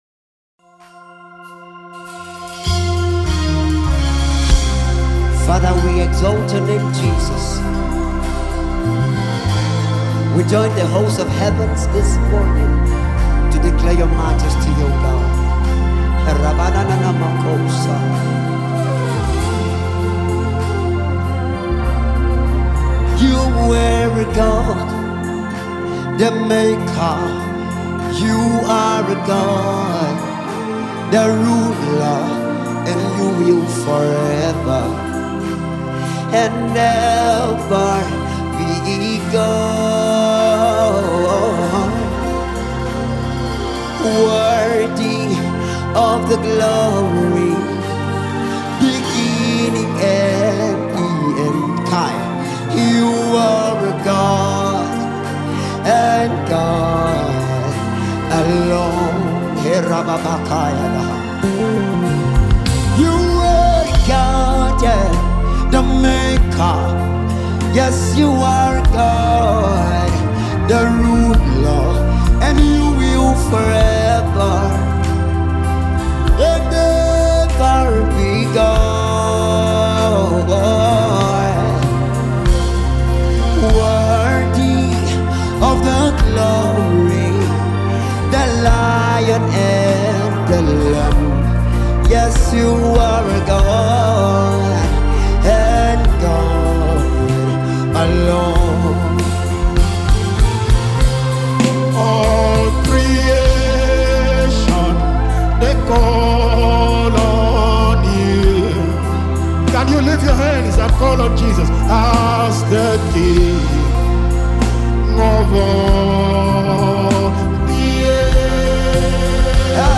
worship sound
delivers a passionate vocal performance